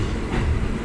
火车轮
描述：这是纽约地铁公交车穿过车站的样车声。
Tag: 地铁 运输 火车 NYC